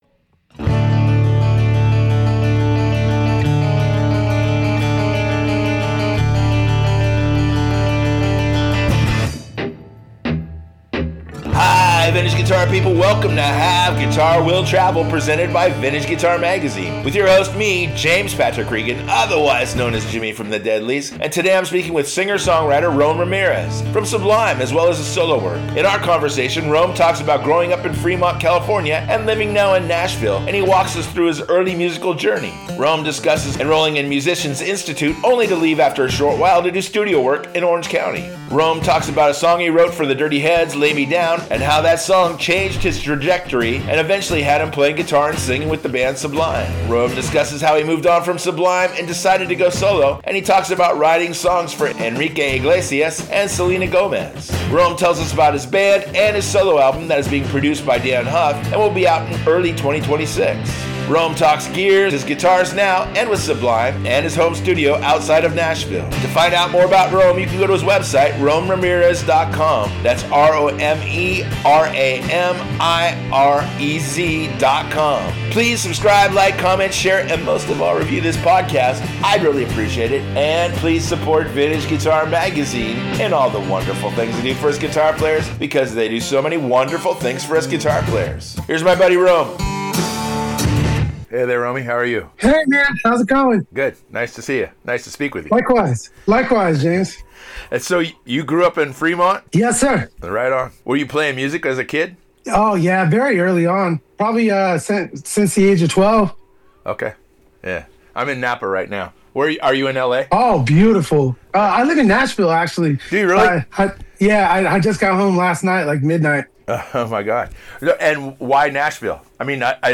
In their conversation Rome talks about growing up in Fremont, California and living now in Nashville and he walks us through his early musical journey.